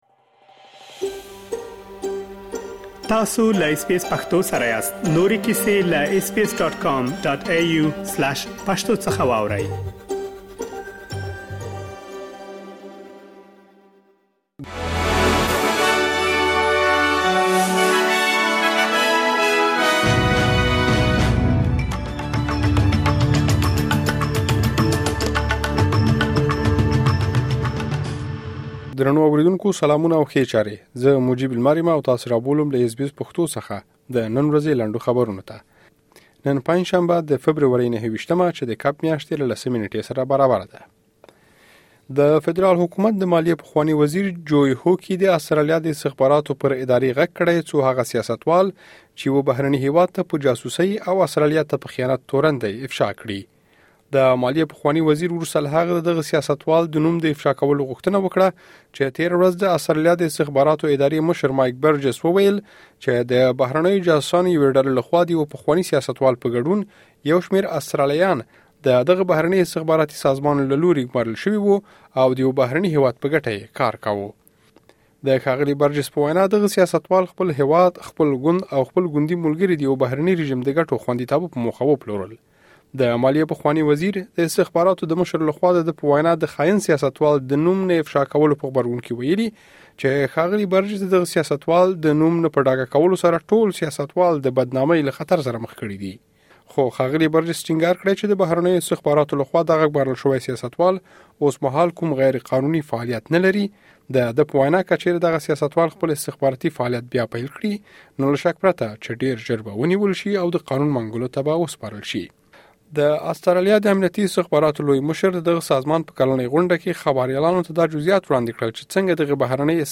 د اس بي اس پښتو راډیو د نن ورځې لنډ خبرونه| ۲۹ فبروري ۲۰۲۴